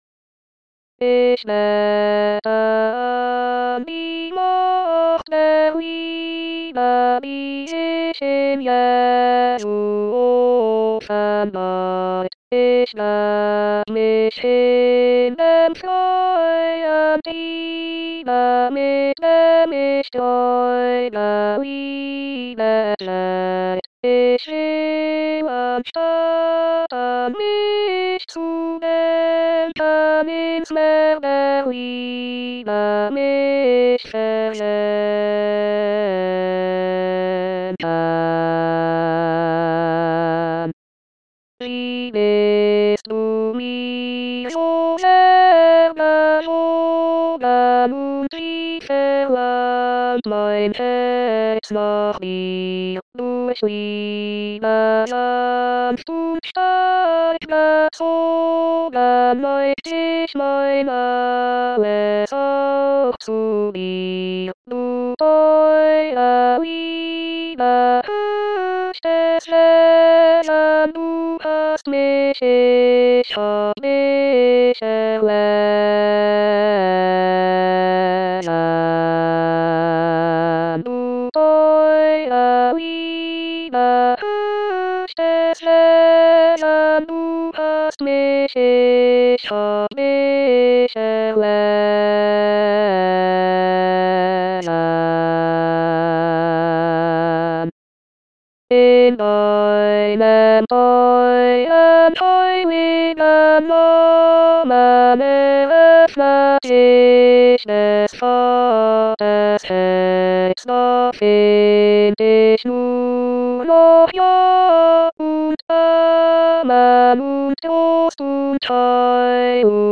ich bete an die Macht der Liebe tenors 1.mp3